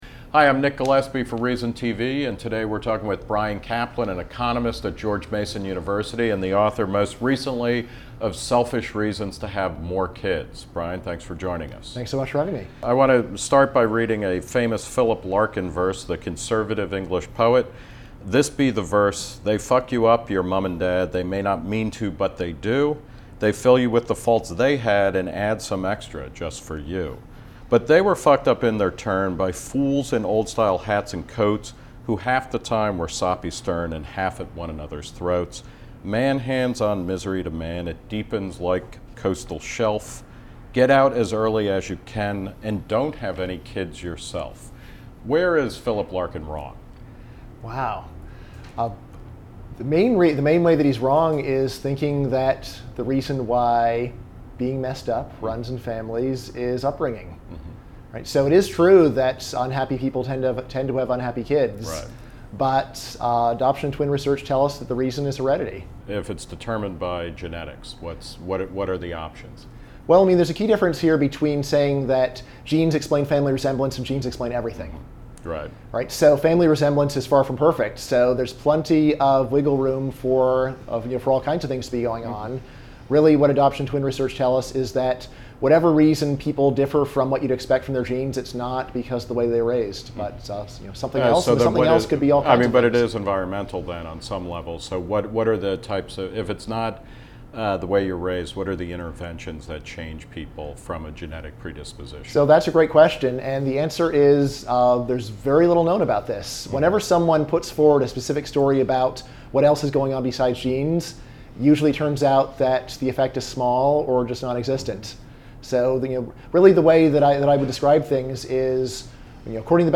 Do Parents Matter? Q&A with Bryan Caplan, Author of Selfish Reasons to Have More Kids
Reason's Nick Gillespie sat down with Caplan to discuss the research behind and the reception of his unconventional theory.